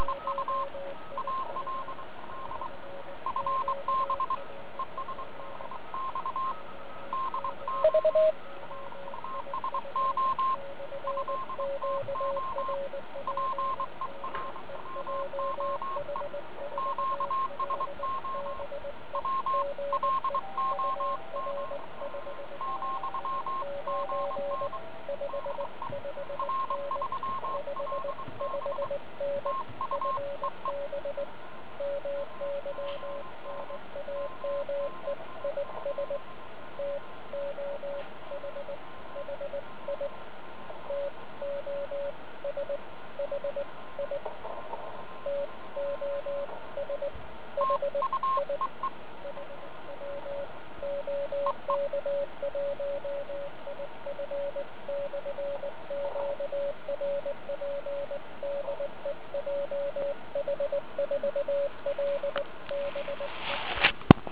Jak vypadá spojení na 40m do Japonska s 5W je nahráno zde: